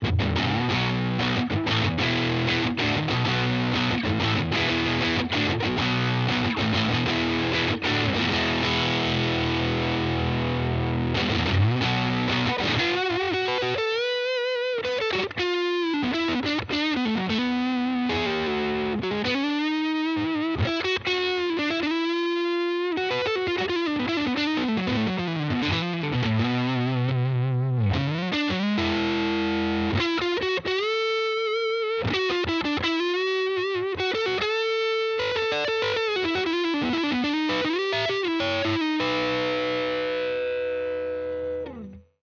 Muse kit micros HepCat série L configuration câblage Memphis position manche+chevalet saturé Muse kit micros HepCat série L configuration câblage Memphis position chevalet Fuzz
La-Muse-5-Sat-Bridge-Neck.wav